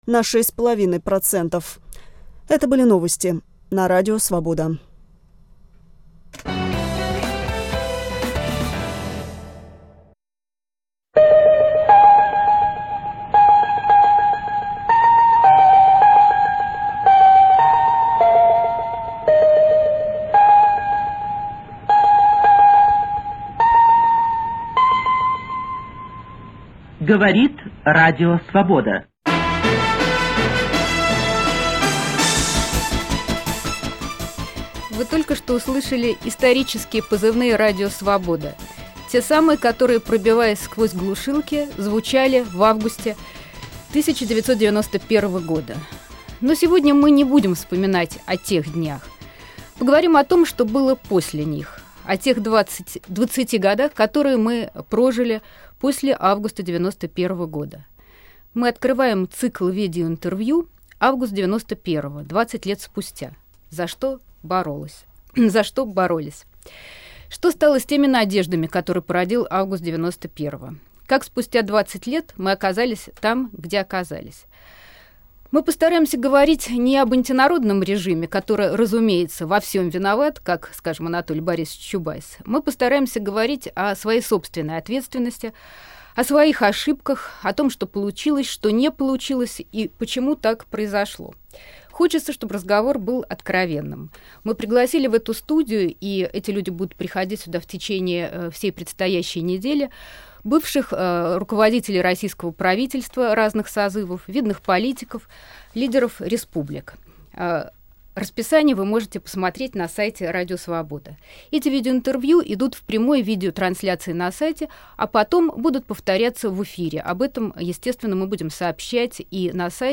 Так называется проект Радио Свобода – цикл прямых видеоинтервью, которые прошли на сайте РС.